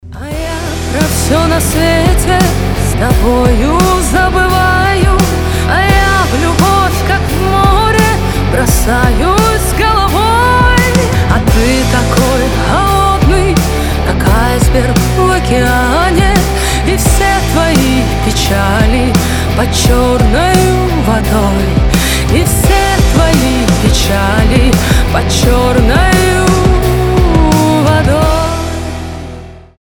• Качество: 320, Stereo
женский голос
Cover
легкий рок